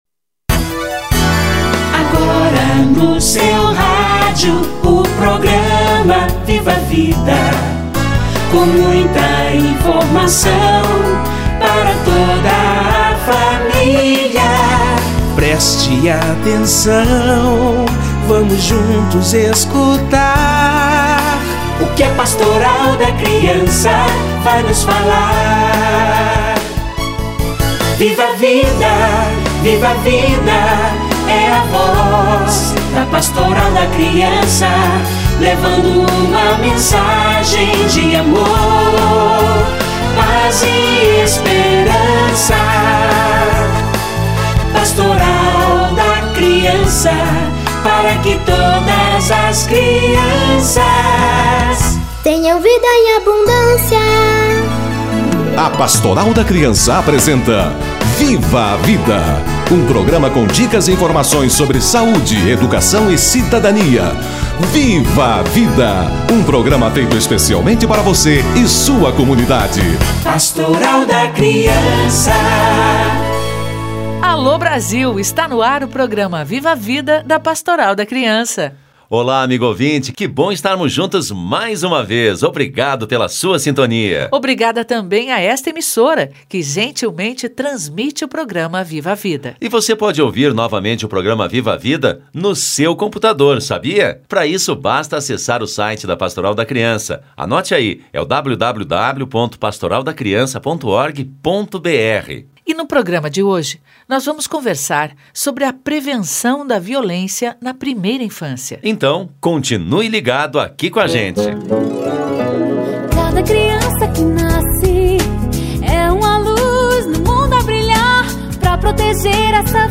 Semana Nacional de Prevenção da Violência na Primeira Infância - Entrevista